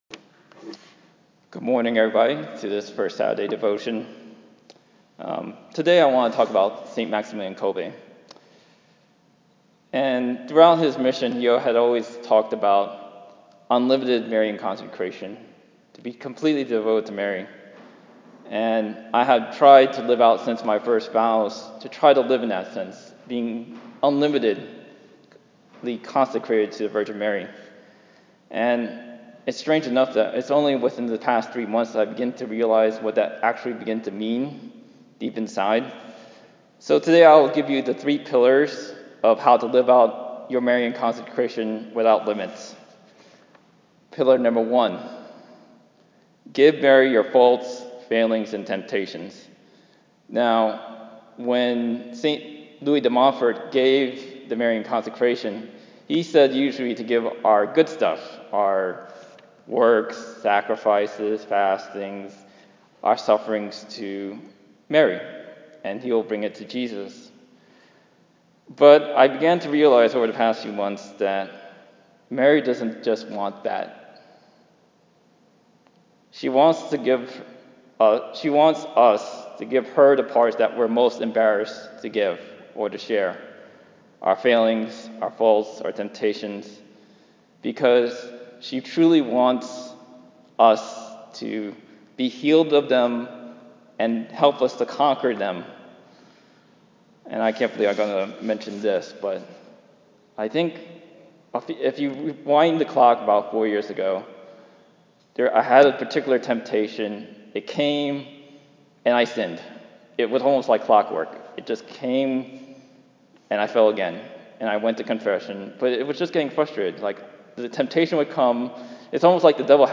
I was surprised how I began to choke up during the third pillar.